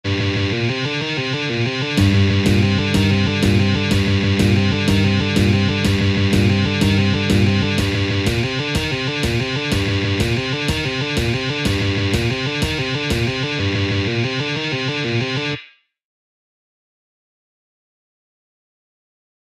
А вот этот рифак для тренировки мизинца хорошо подходит